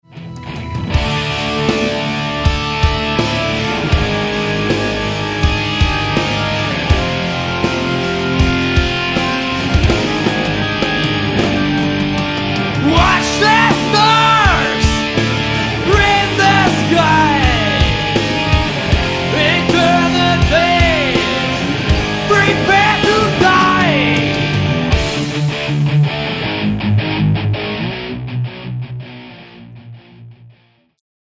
Fade/Fate Proberaum Demo